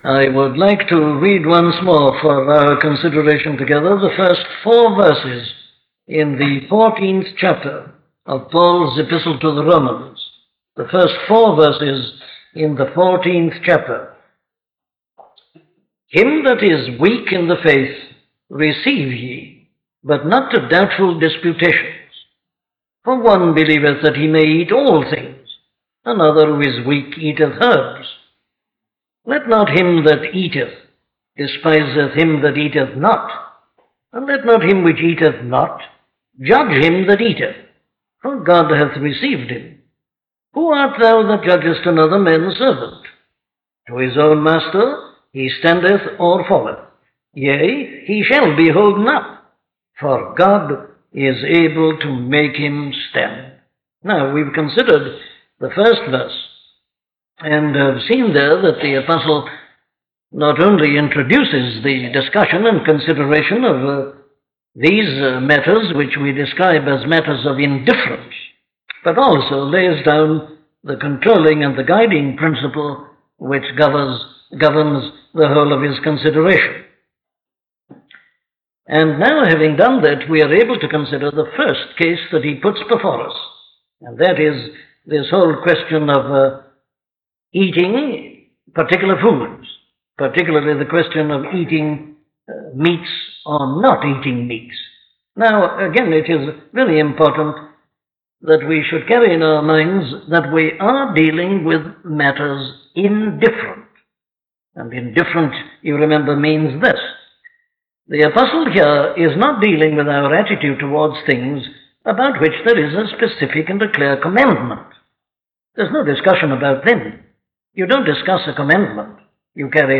Dr Martyn Lloyd-Jones’s sermons on the book of Romans were preached to the congregation at Westminster Chapel in the heart of central London on Friday evenings between October 1955 to March 1968.